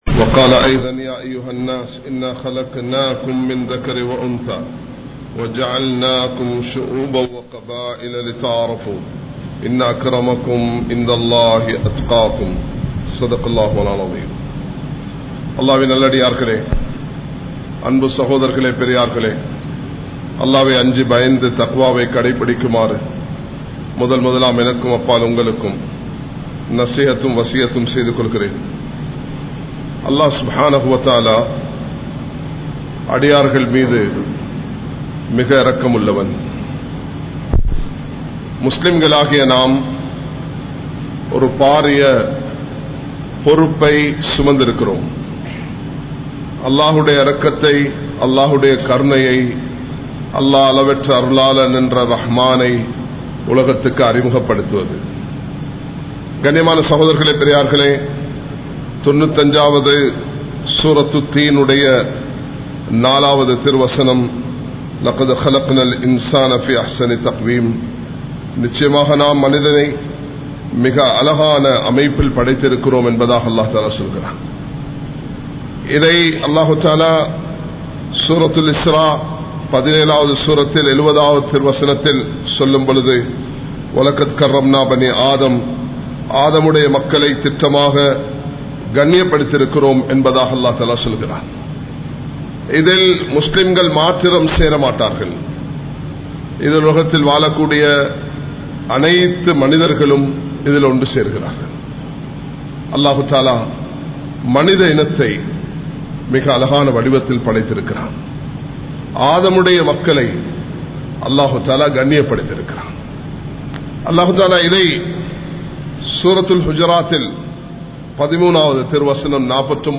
Muslimkale! Maarkaththil Pala Vantham Kidaiyathu (முஸ்லிம்களே! மார்க்கத்தில் பலவந்தம் கிடையாது) | Audio Bayans | All Ceylon Muslim Youth Community | Addalaichenai
Colombo 03, Kollupitty Jumua Masjith